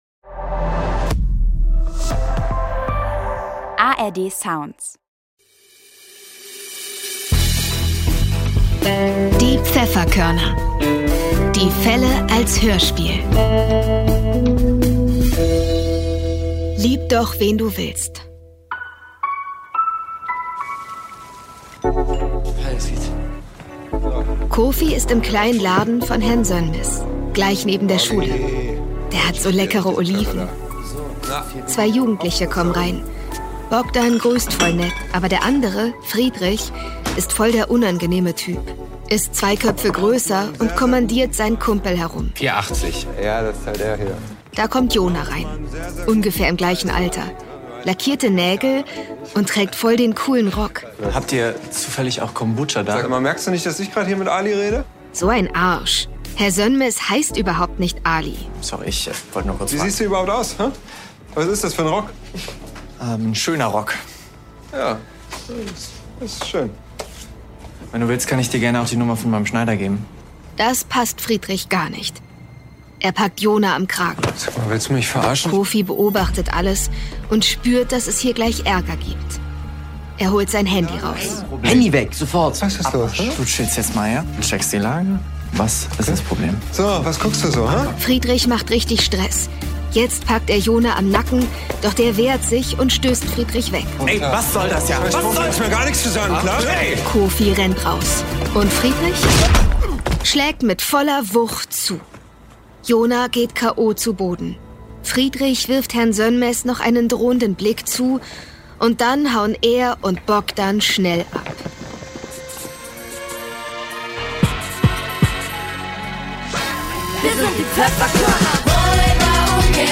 Lieb doch, wen du willst (19/21) ~ Die Pfefferkörner - Die Fälle als Hörspiel Podcast